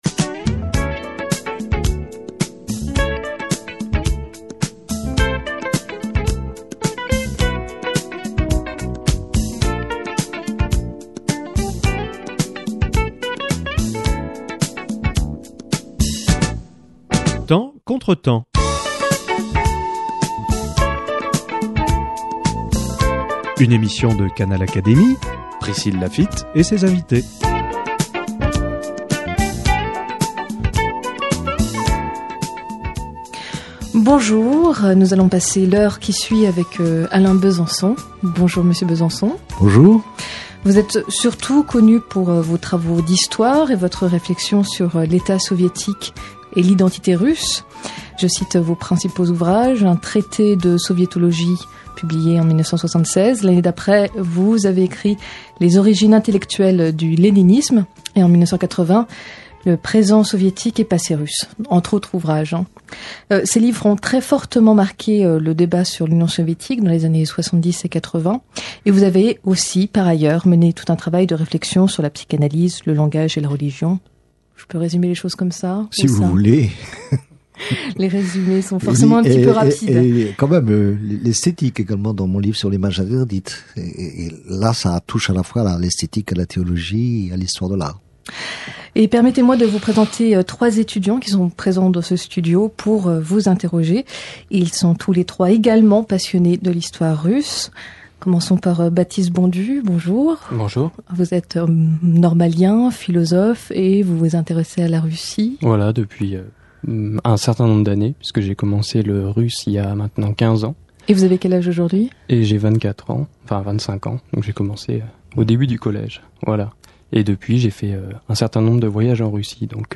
Trois étudiants passionnés de Russie interrogent Alain Besançon sur son parcours, son point de vue sur la Russie actuelle, et partagent leur difficulté à mener des recherches de l’union soviétique, l’ouverture des archives, etc...